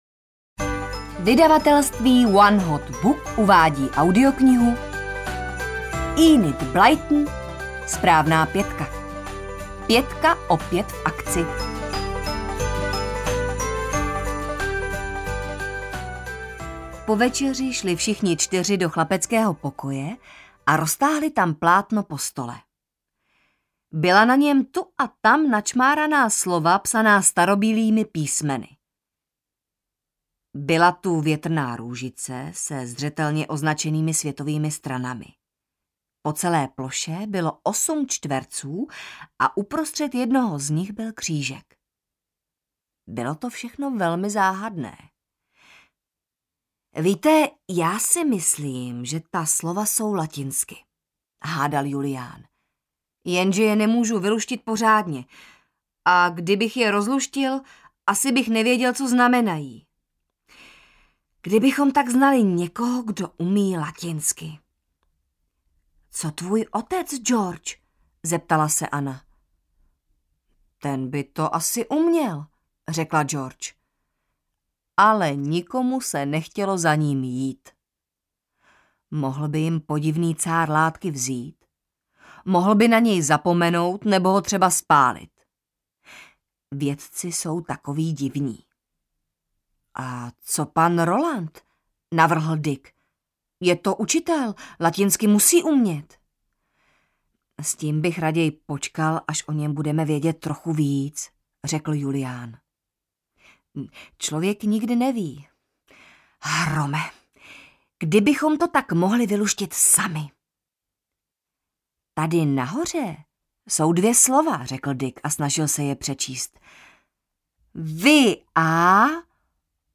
SPRÁVNÁ PĚTKA opět v akci audiokniha
Ukázka z knihy